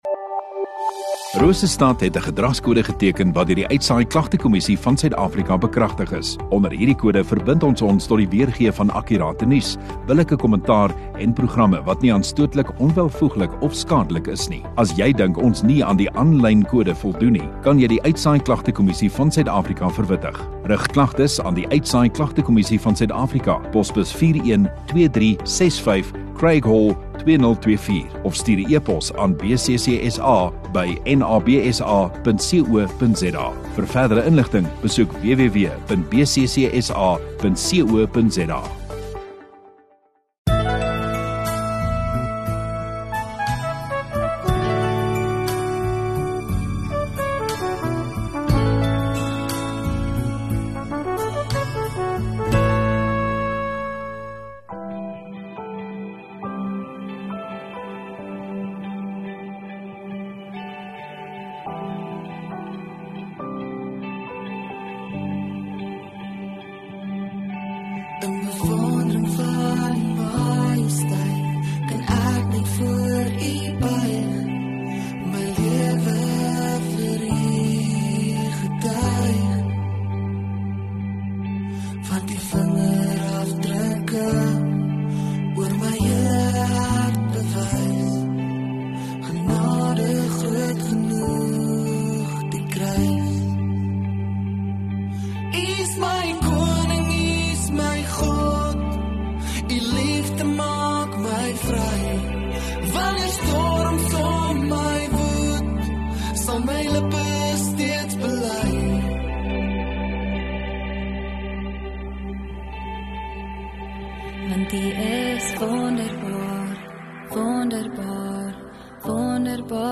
31 Dec Dinsdag Oggenddiens